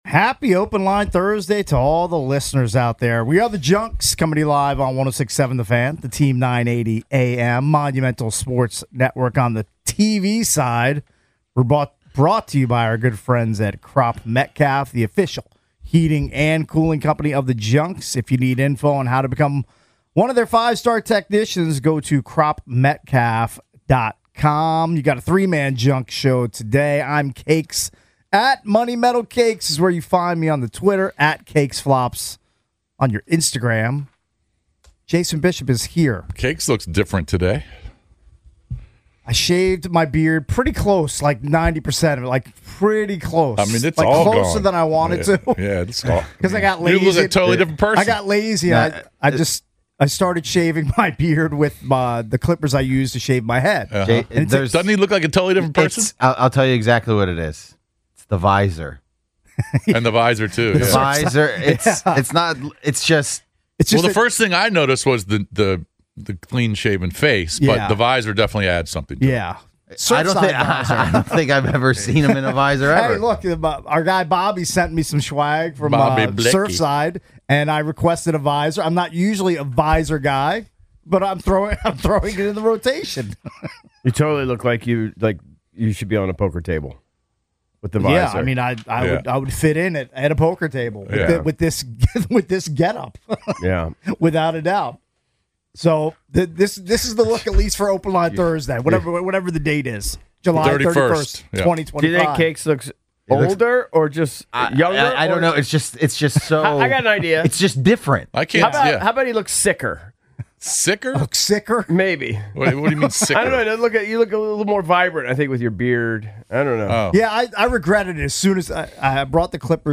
Sports, Comedy